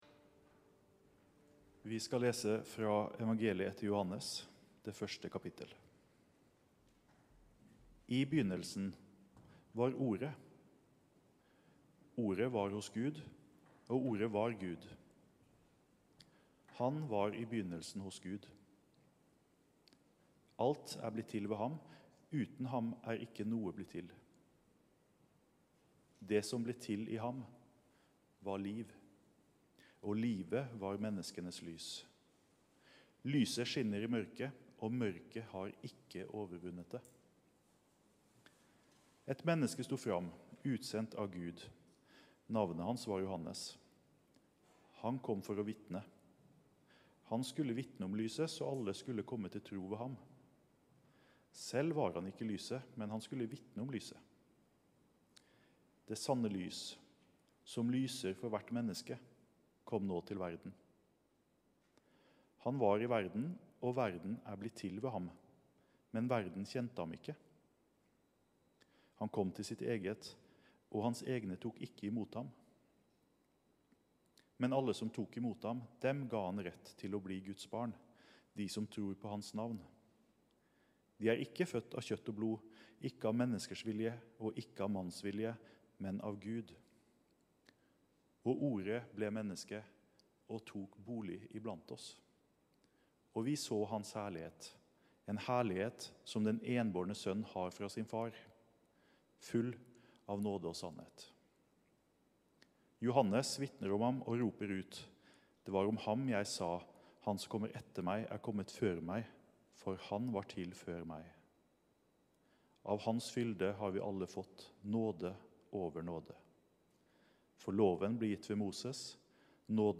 Gudstjeneste 17. januar 2020, Jesus er Ordet | Storsalen
Velkommen-til-gudstjeneste-17.-januar.mp3